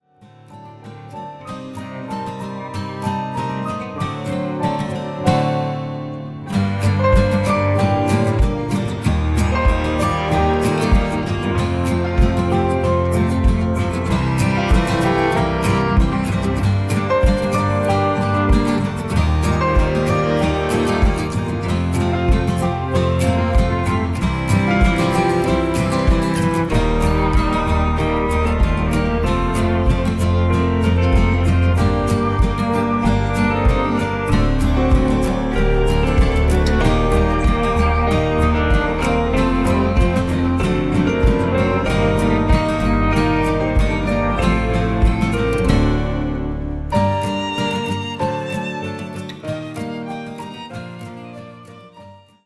sans voix petite fille